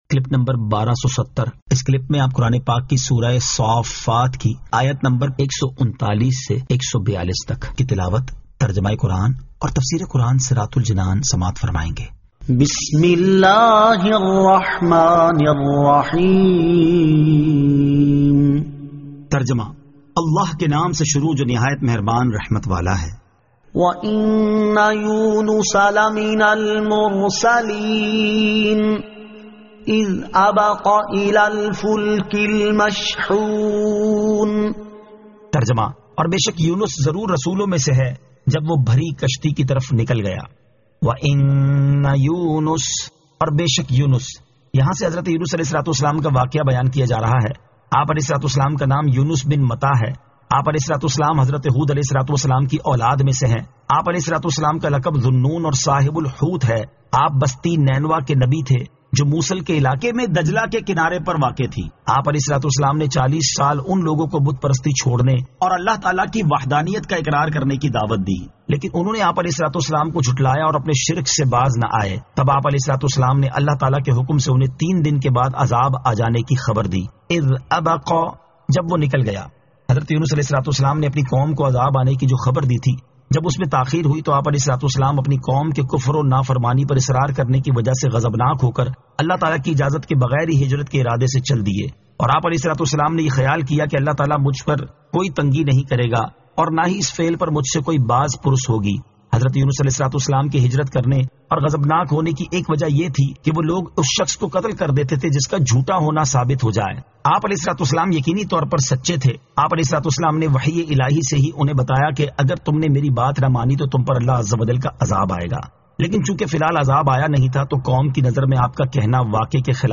Surah As-Saaffat 139 To 142 Tilawat , Tarjama , Tafseer